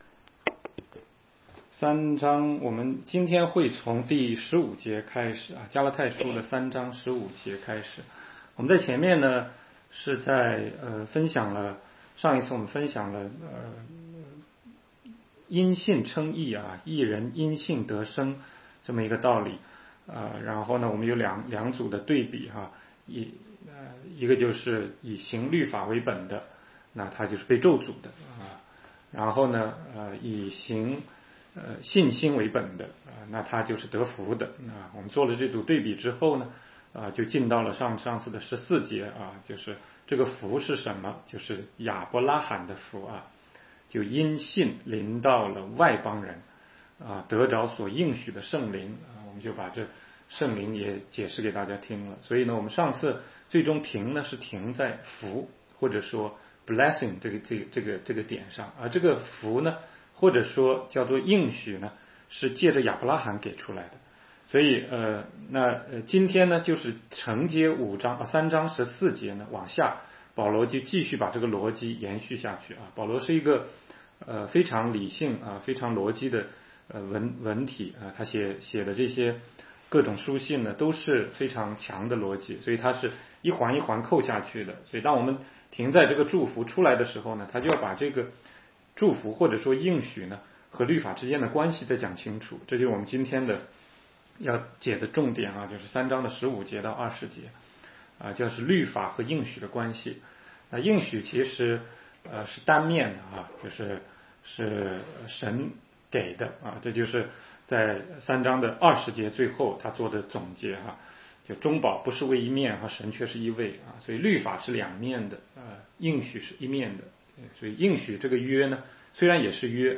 16街讲道录音 - 加拉太书3章15-20节：应许之约不会被律法废去